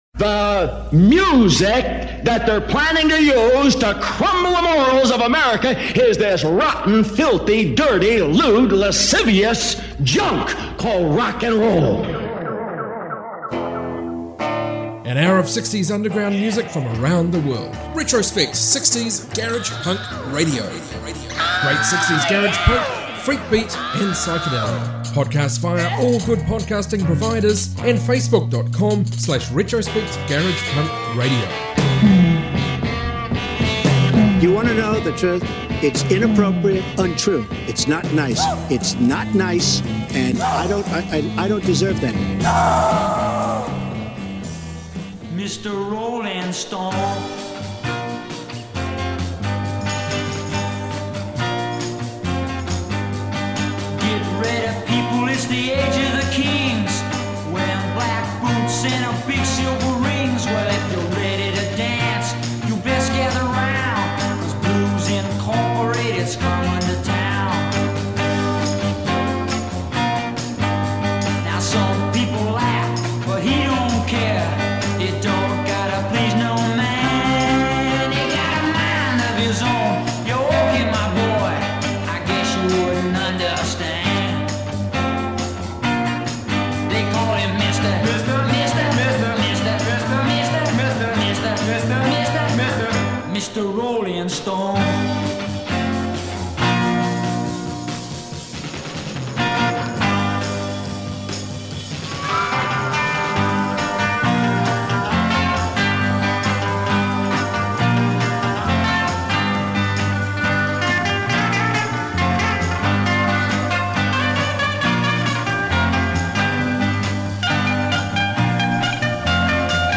60s garage punk, garage rock, freakbeat from around the globe